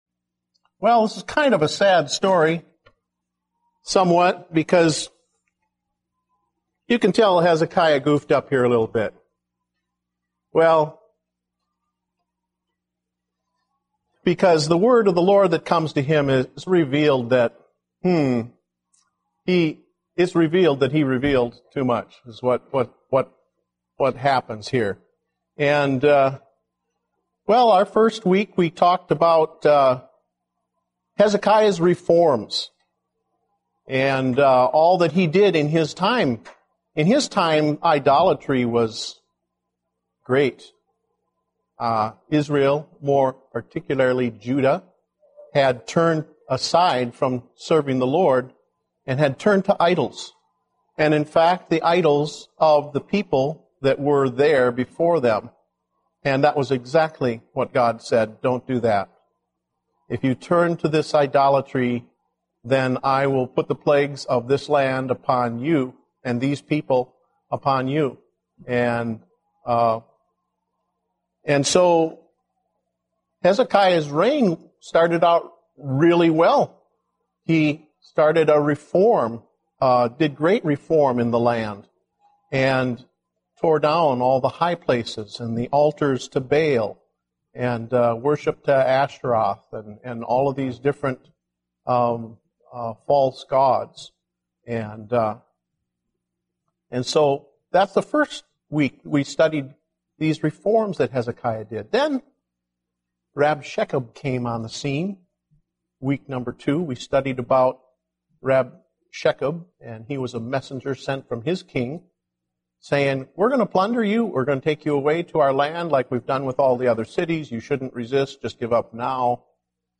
Date: February 28, 2010 (Adult Sunday School)